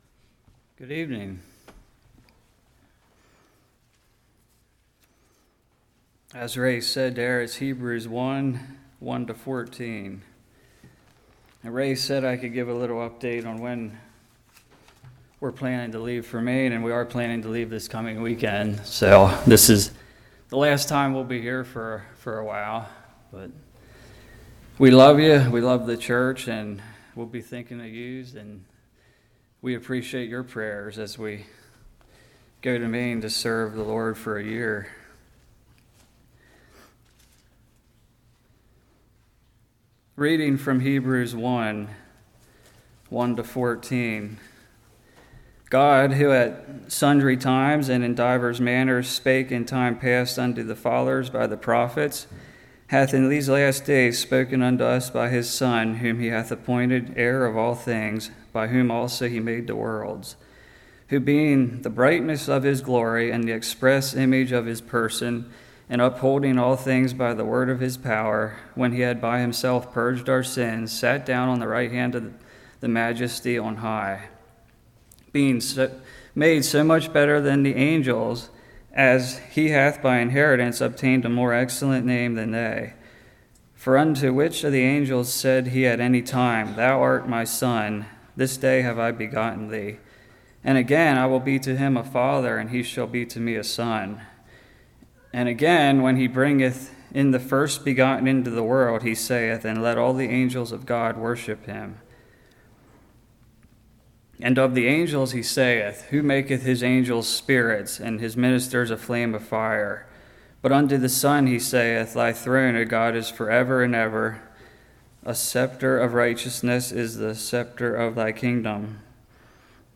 Hebrews 1:1-14 Service Type: Evening Satan can appear as an angel of Light but when aligned with scripture and truth he will be exposed.